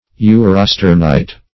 Definition of urosternite.
urosternite.mp3